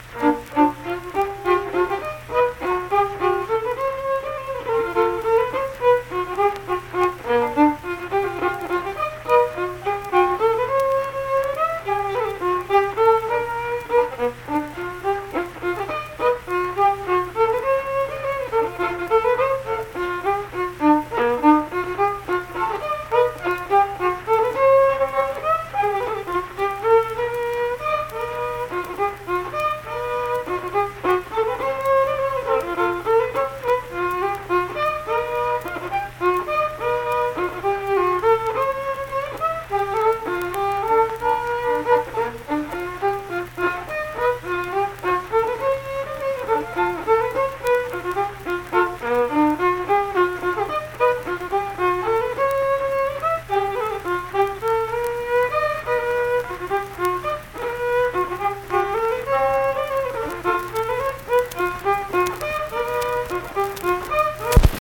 Unaccompanied vocal and fiddle music
Instrumental Music
Fiddle
Pleasants County (W. Va.), Saint Marys (W. Va.)